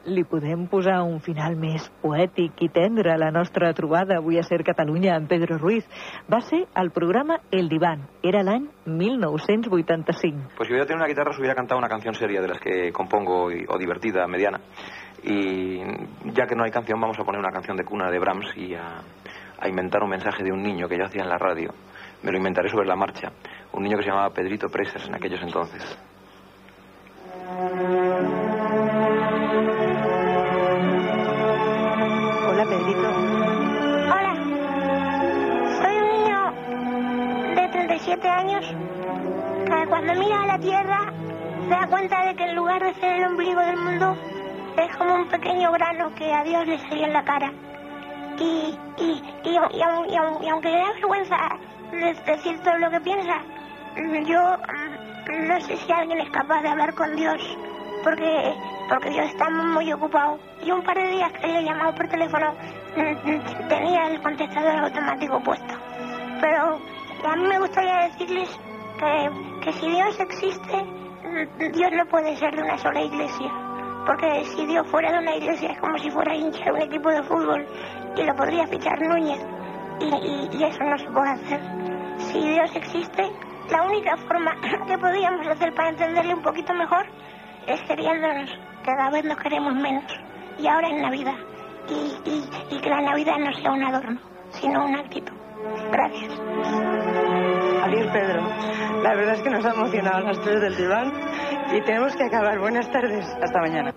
En el pograma "El diván" de Ràdio Barcelona Pedro Ruiz imita un nen petit .
FM